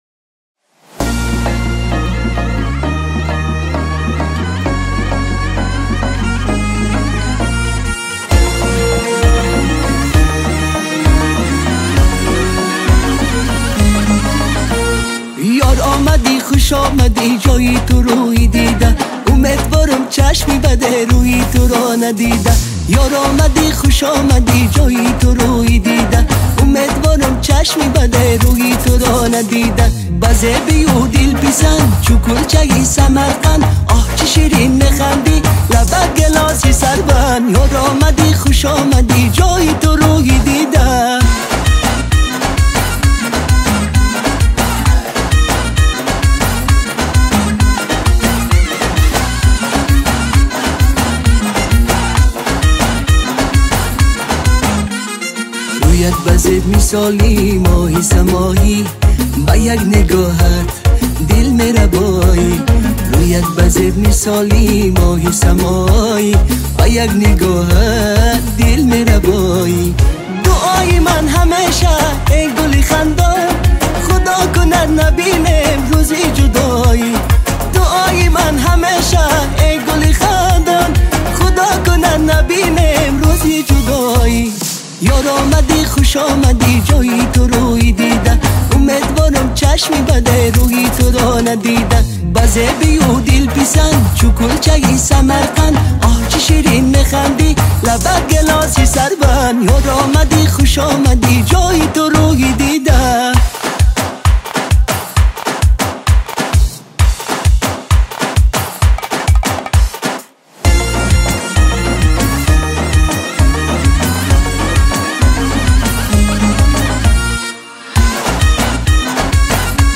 • Категория: Таджикские песни